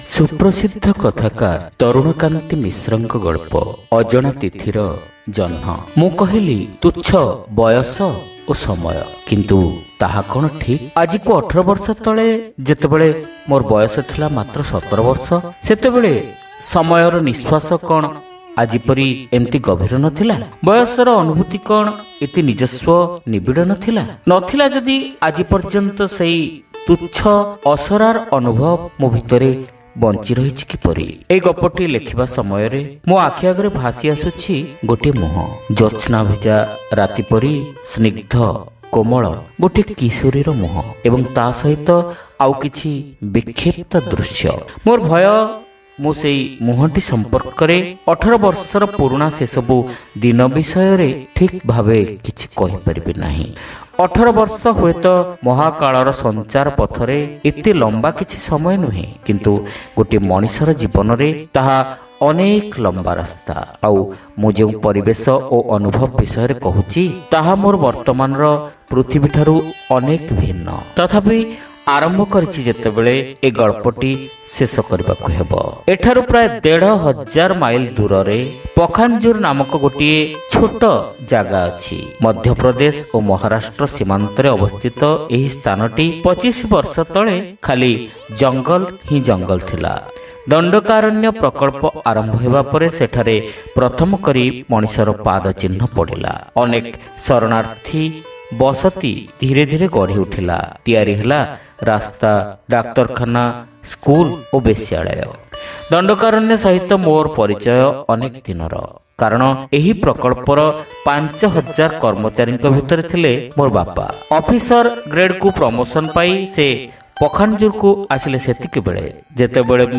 ଶ୍ରାବ୍ୟ ଗଳ୍ପ : ଅଜଣା ତିଥୀର ଜହ୍ନ (ପ୍ରଥମ ଭାଗ)